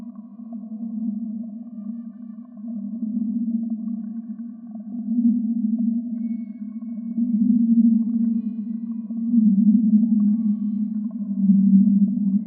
The back-to-back brick wall filters work like a CHARM!!!
This is me playing some violin scales..... check it out now.